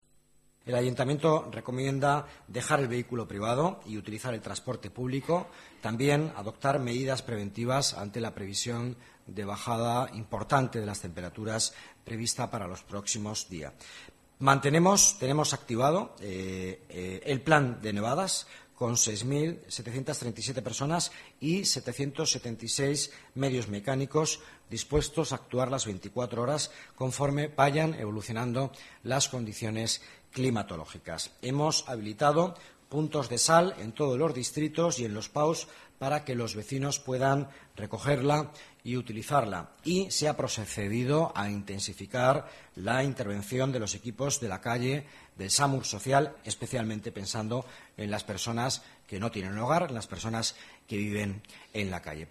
Nueva ventana:Declaraciones del vicealcalde, Miguel Ángel Villanueva